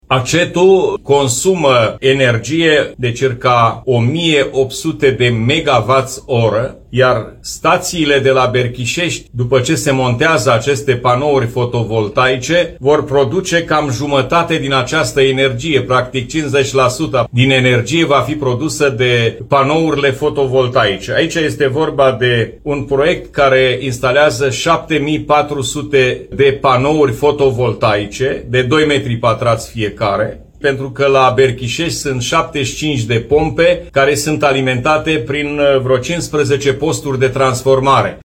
Președintele Consiliului Județean Suceava GHEORGHE FLUTUR a declarat că valorea investiției este de 3 milioane și jumătate euro.